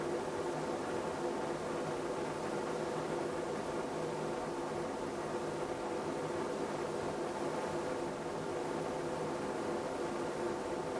From 315 to 500 Hz, noise increases compared to the rest of the frequencies.
I have recorded the signals shown above, but please keep in mind that I’ve enabled Automatic Gain Control (AGC) to do so to make it easier for you to reproduce them.
25% Fan Speed